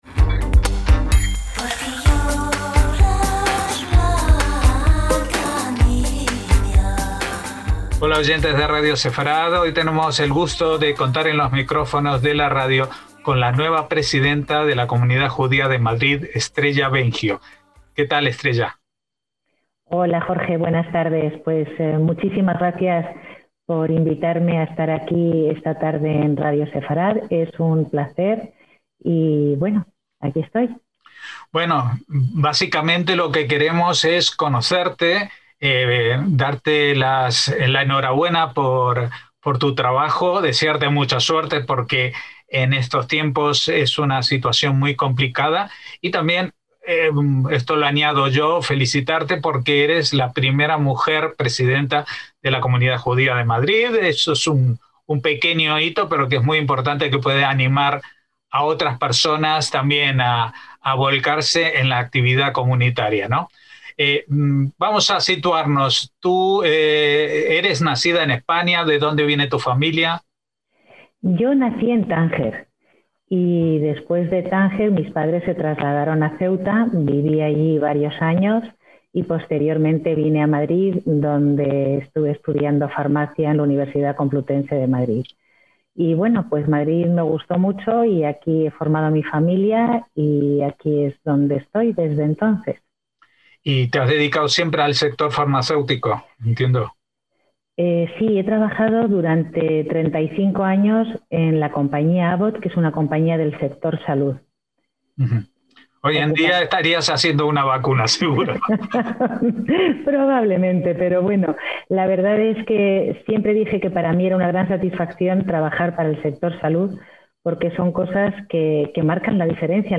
Pero siempre, nos confiesa en este, su primera entrevista para Radio Sefarad, con ganas de participar más activamente en la vida comunitaria judía.